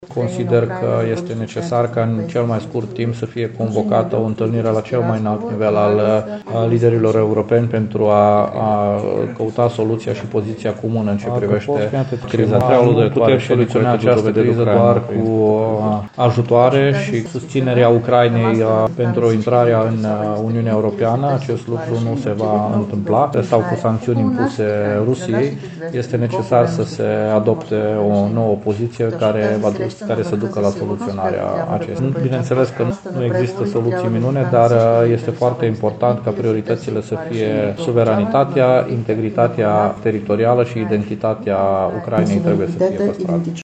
Prezentă zilele acestea în vestul ţării, pentru a participa la evenimente ale comunităţii bulgare din România, Iliana Malinova Iotova a fost abordată de presă şi pe tema conflictului din Ucraina.